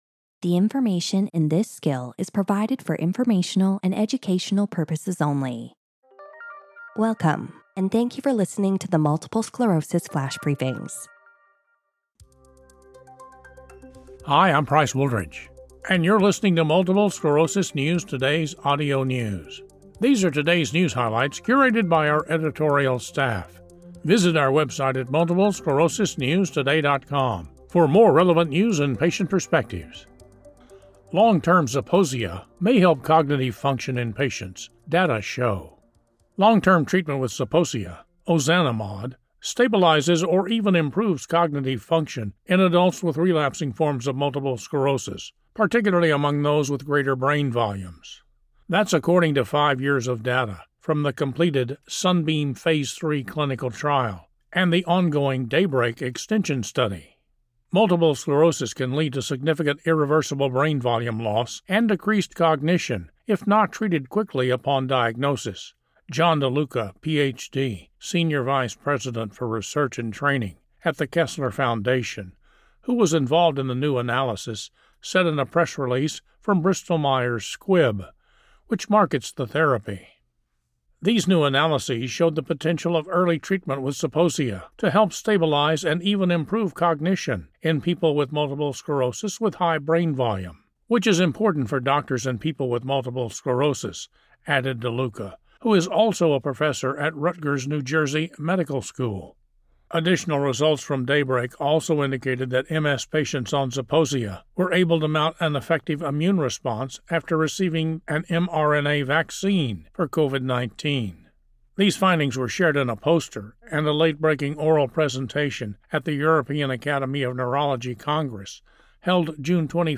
In this audio news episode